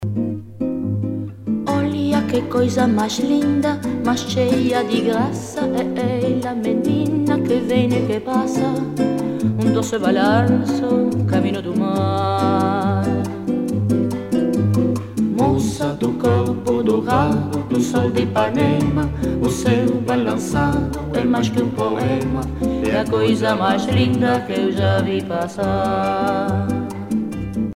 danse : bossa nova
Pièce musicale éditée